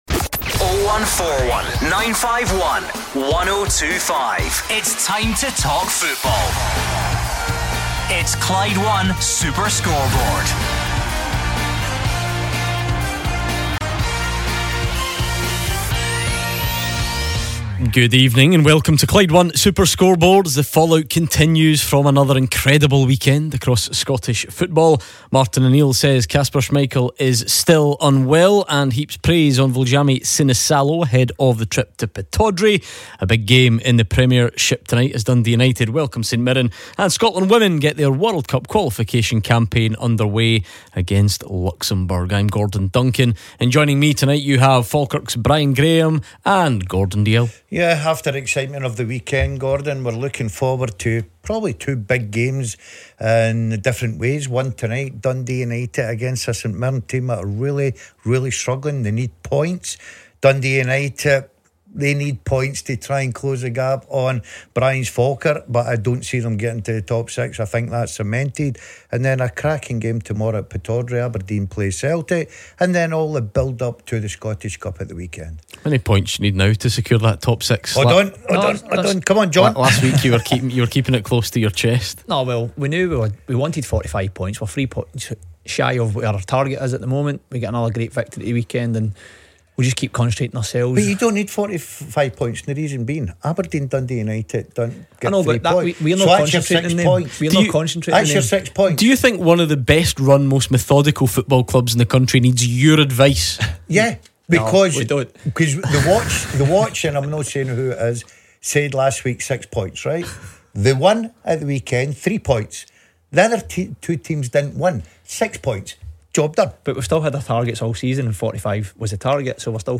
The top team are around the grounds - we gat the full time scores, a look forward to Celtic vs Rangers and the open lines.